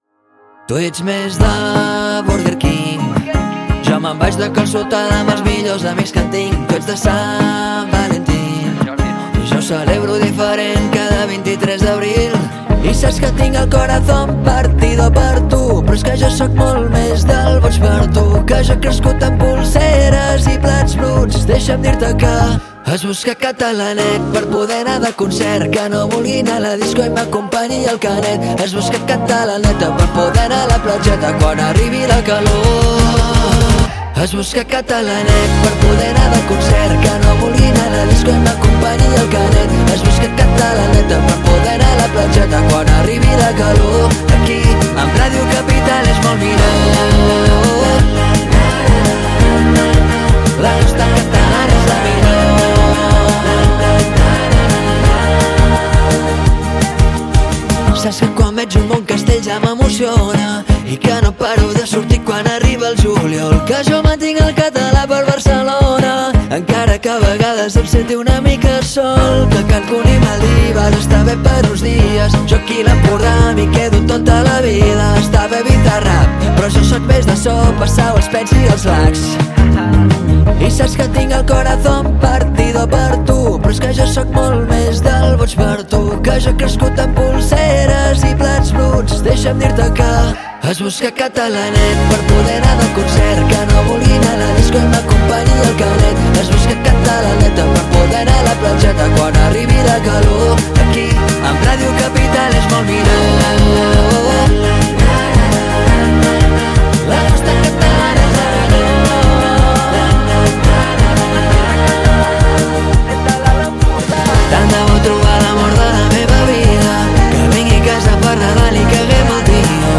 música festiva en català
presenta un so fresc i ballable